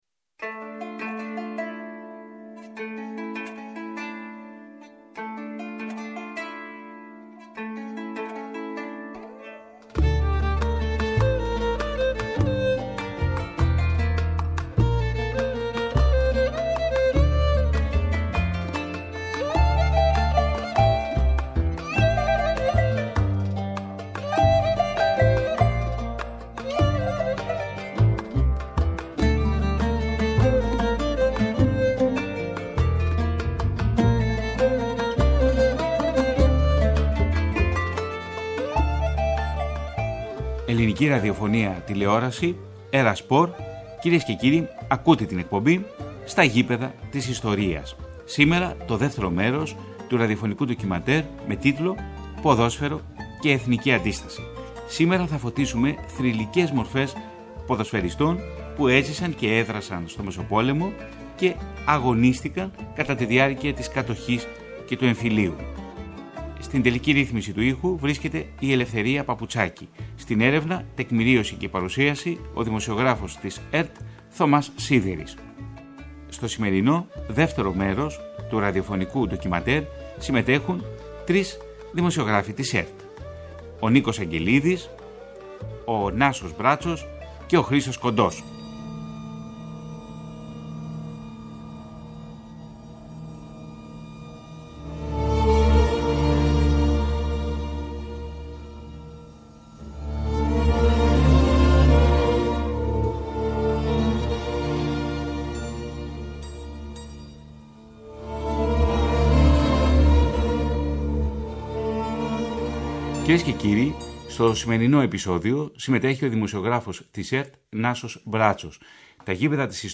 Ένα συγκλονιστικό ραδιοφωνικό ντοκιμαντέρ σε δύο μέρη, που ξετυλίγει την αντιστασιακή δράση συλλογικών φορέων και αθλητών την περίοδο της Κατοχής και του Εμφυλίου.